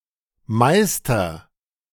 Meister (pronounced [ˈmaɪstɐ]
De-meister.ogg.mp3